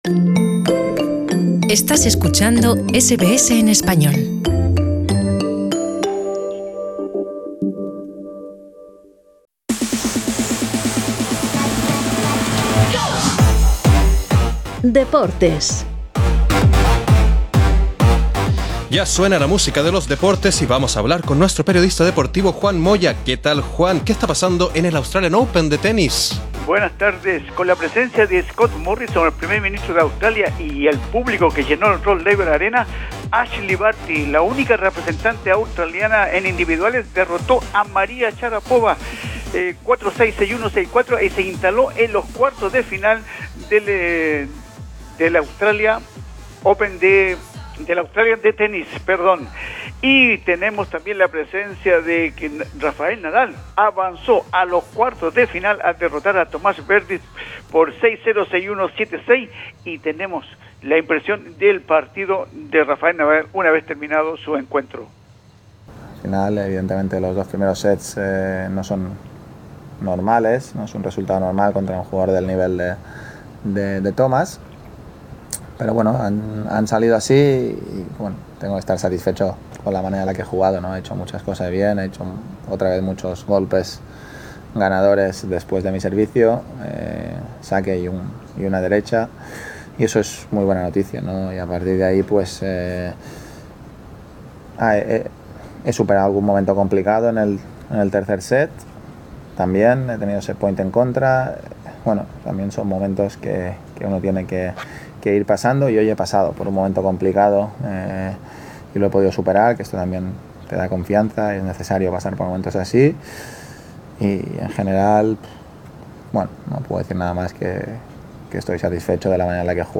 breve informe